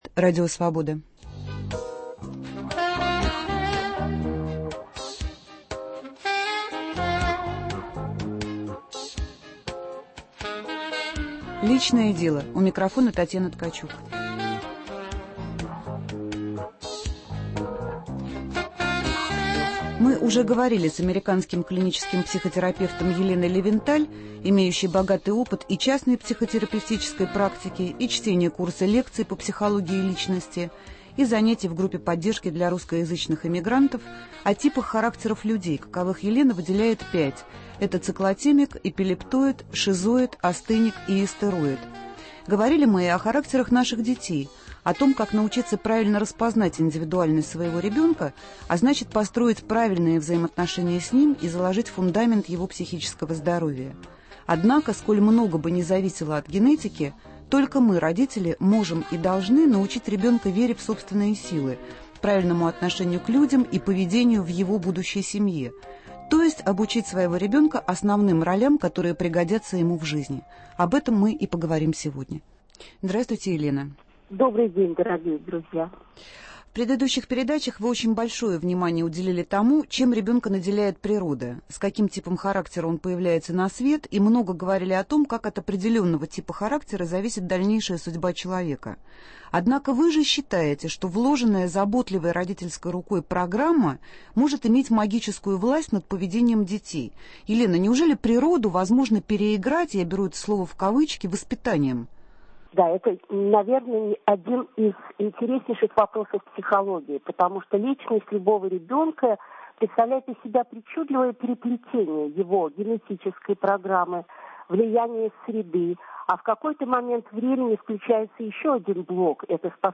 Гость программы - американский клинический психотерапевт